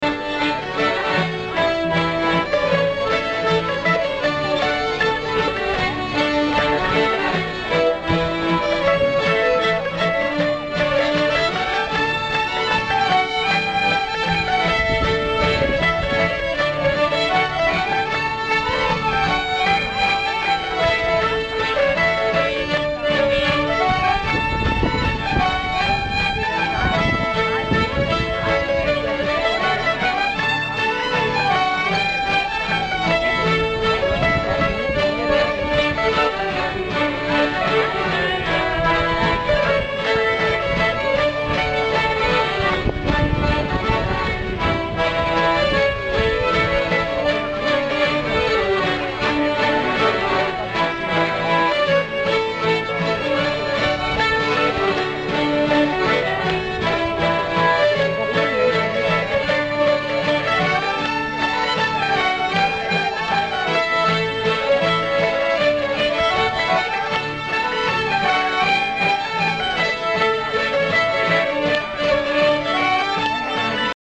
Spil p� torvet 2008
spil paa torvet 2008.mp3